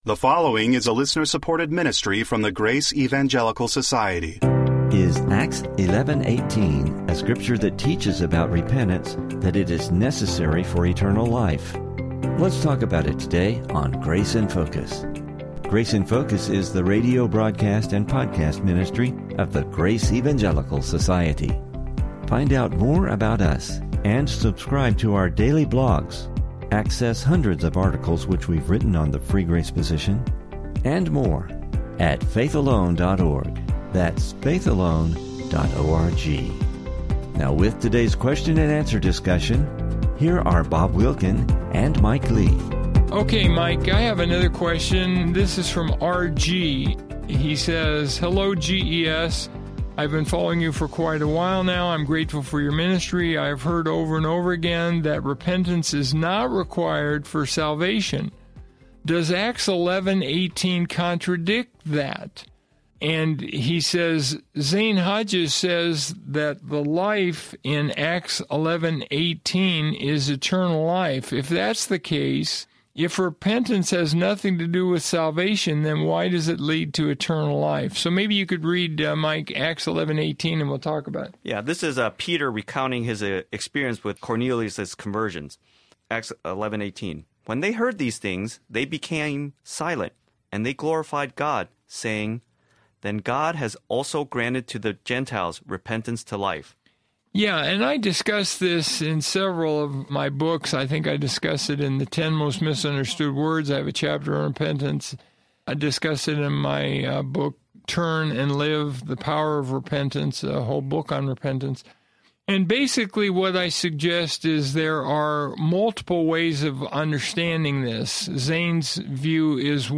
Please listen for an interesting explanation, discussion and clarification on this passage and topic.